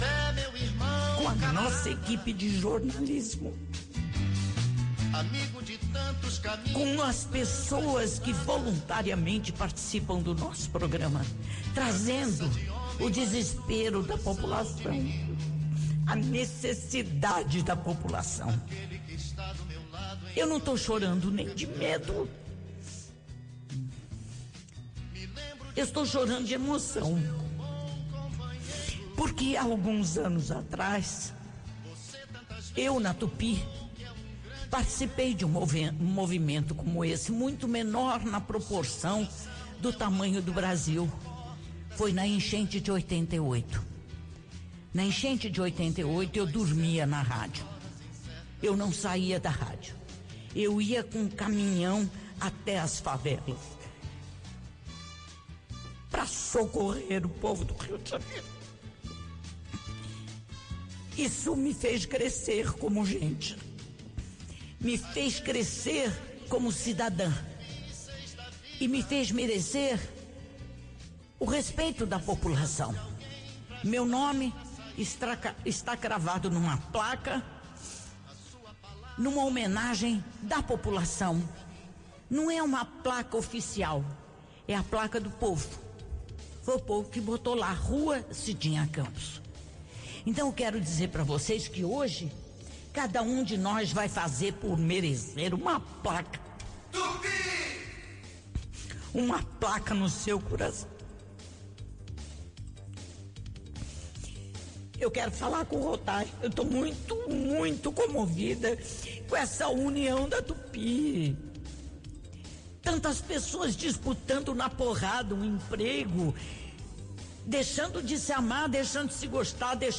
Pela primeira vez, os comunicadores da Rádio Tupi se reuniram em um mesmo programa.
Durante o início da atração, Cidinha Campos, ao lado dos colegas de profissão, se emocionou ao falar sobre a cobertura jornalística da emissora.